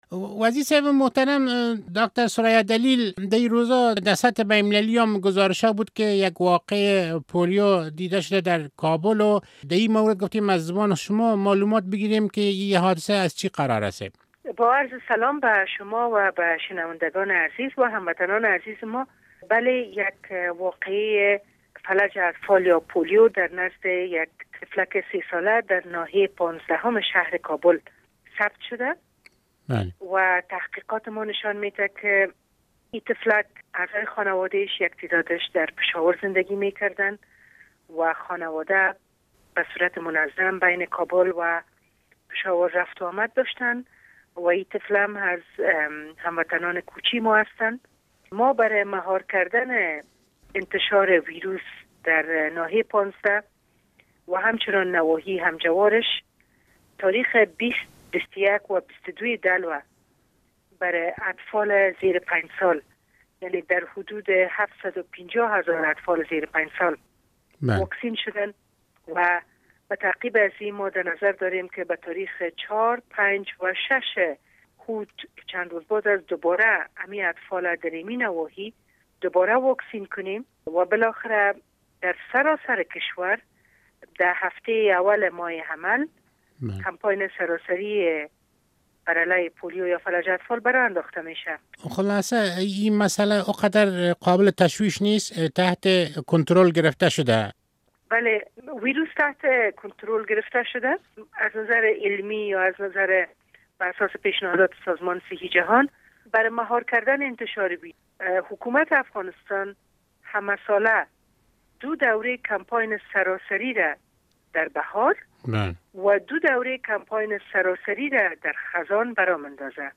مصاحبه با ثریا دلیل وزیر صحت عامه در مورد تازه ترین مورد مریضی پولیو در افغانستان